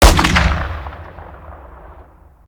weap_br2_fire_plr_atmo_ext1_04.ogg